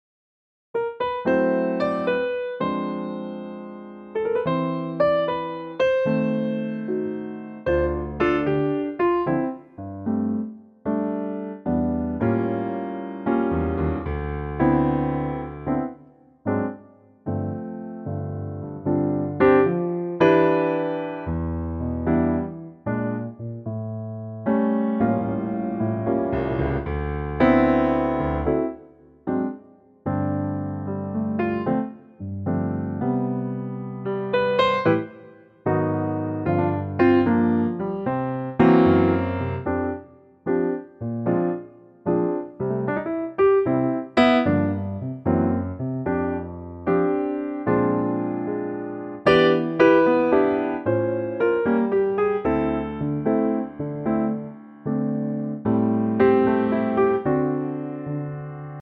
key - C - vocal range - G to A
Here's a piano only arrangement.